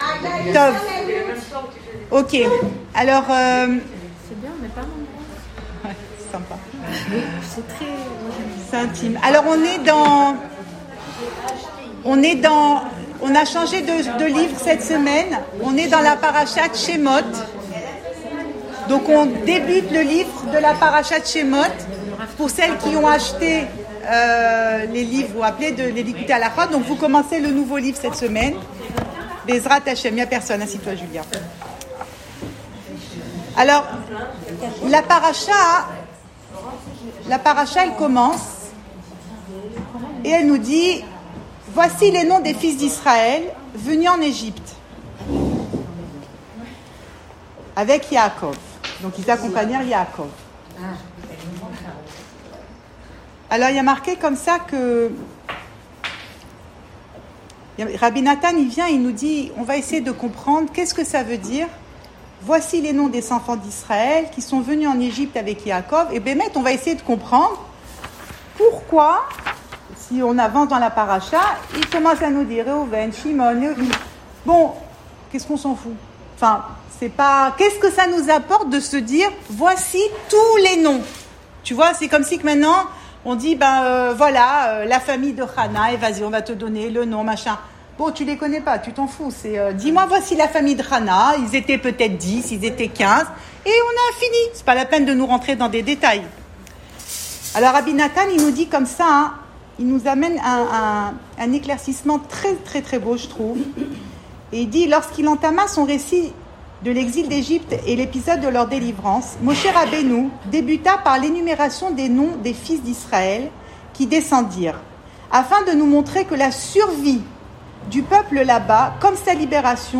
Cours audio Le coin des femmes Pensée Breslev - 6 janvier 2021 8 janvier 2021 L’Egypte, et depuis… Enregistré à Tel Aviv